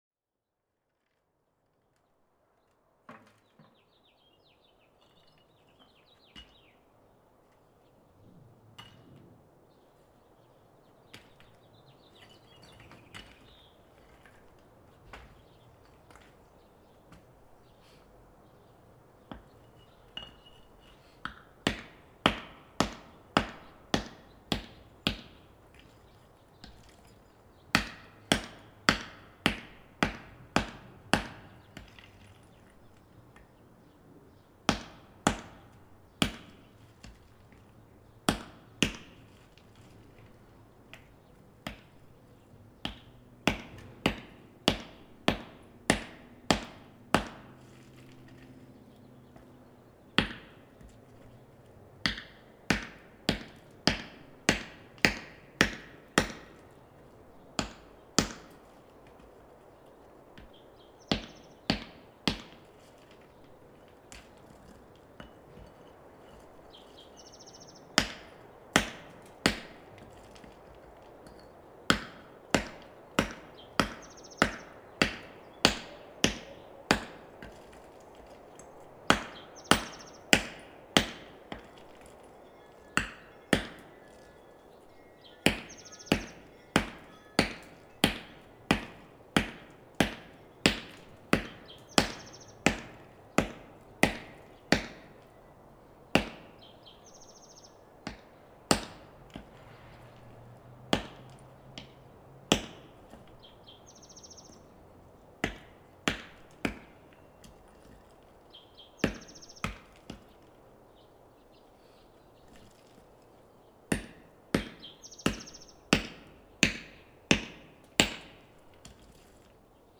2019 reveil dawn chorus